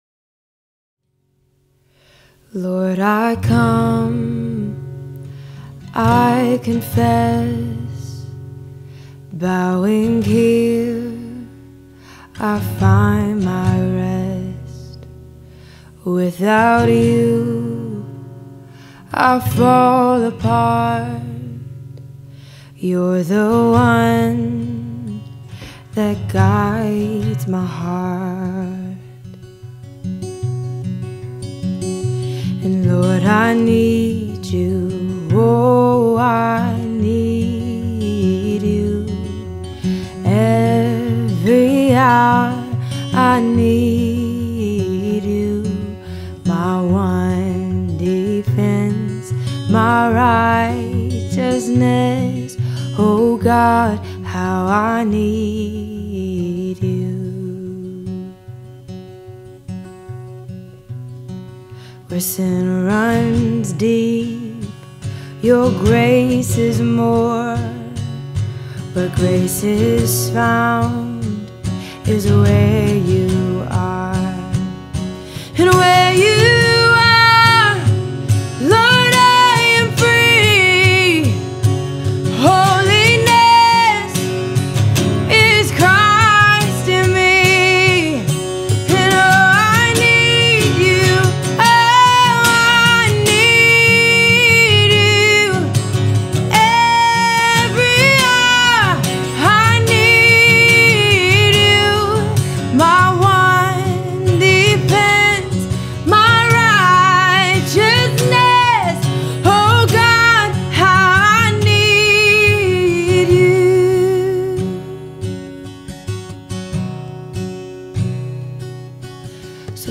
3. Sunday Worship – Second Song: